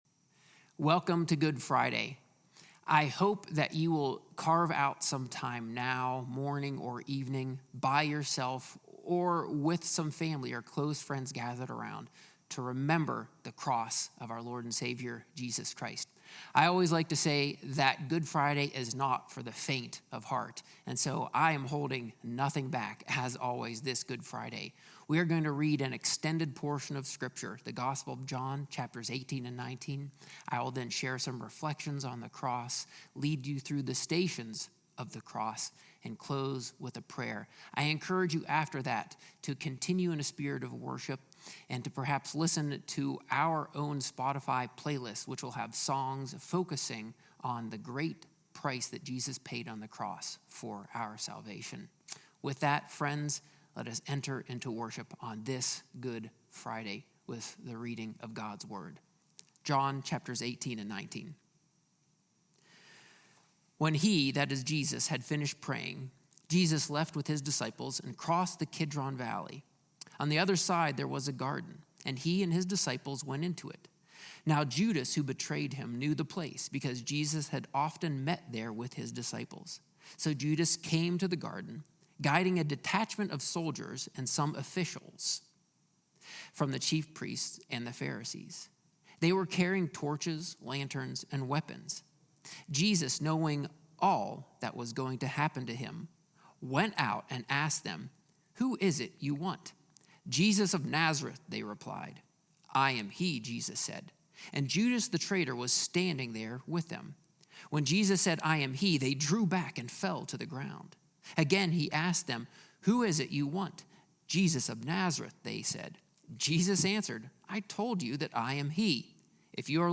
A sermon from the series "Christmas In Focus."